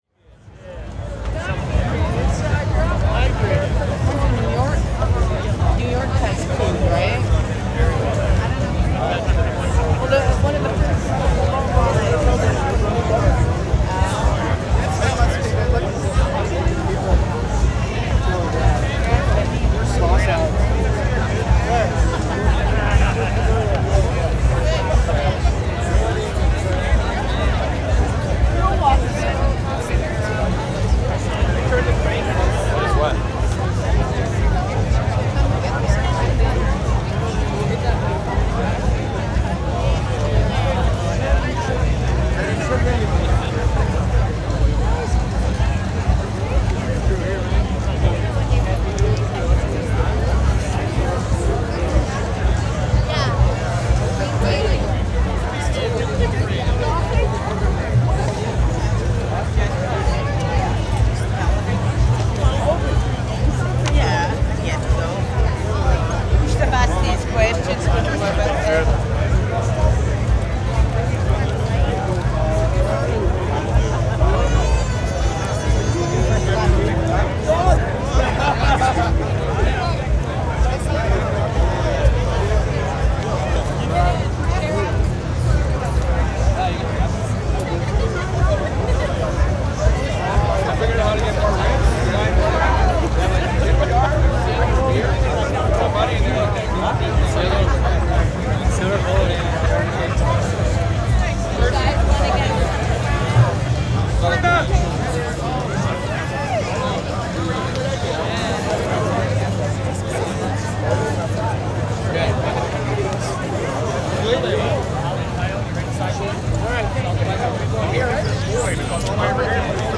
streetparty3.R.wav